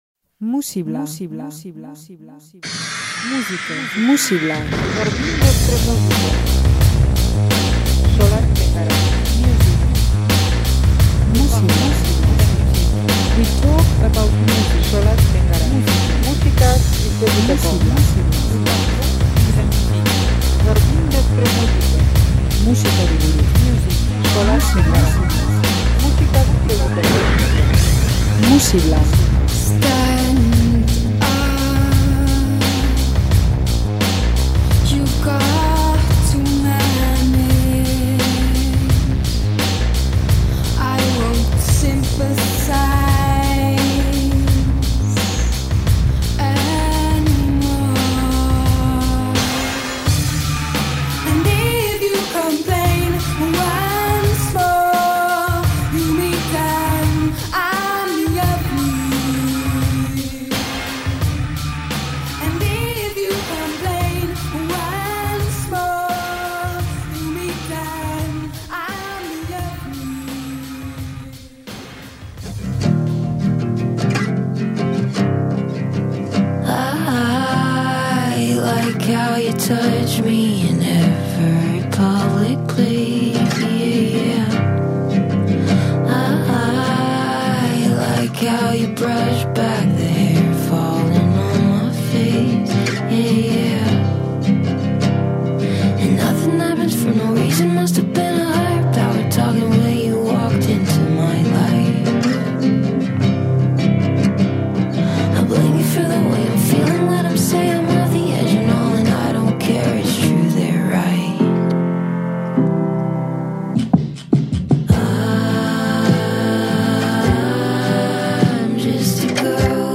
Talde Britainiar hau beste rock talde batzuetatik bereizten da dance punk soinu batean tematu delako, eta bere musika underground garito batean imajinatzen duelako, handi-mandikeriarik gabe.